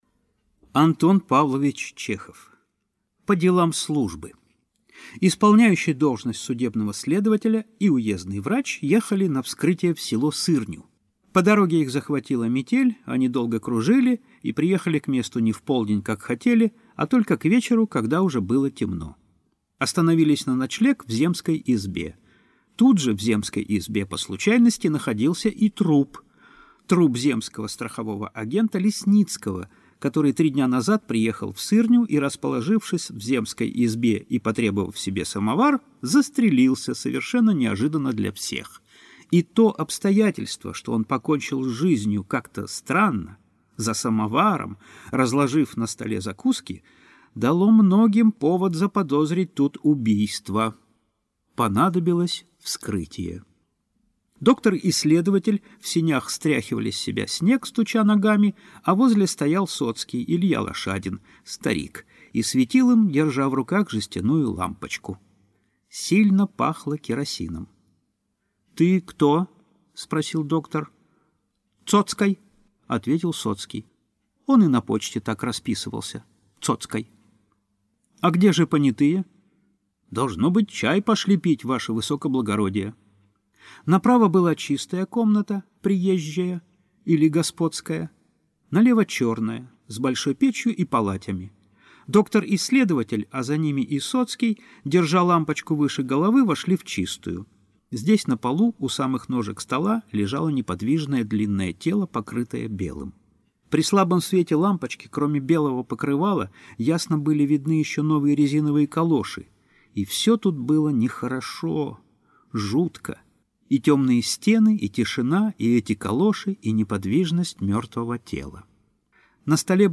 Аудиокнига По делам службы | Библиотека аудиокниг